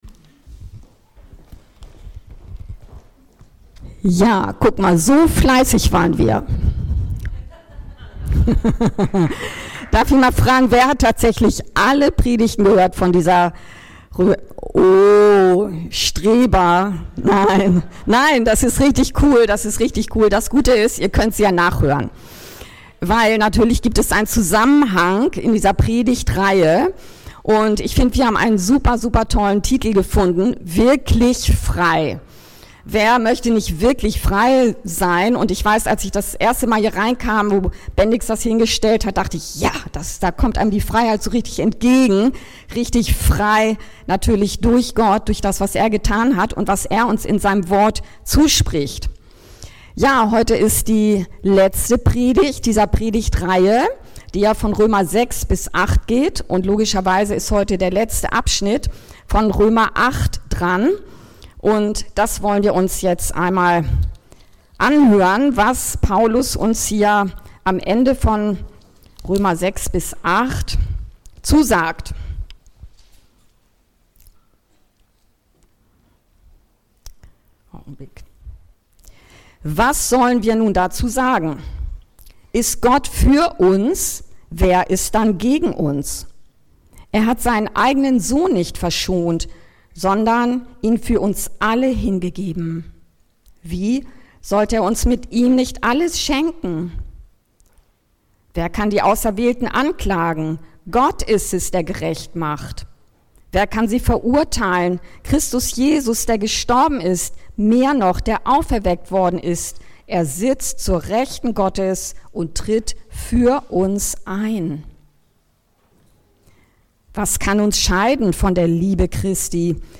Wirklich frei durch Gottes Liebe! ~ Anskar-Kirche Hamburg- Predigten Podcast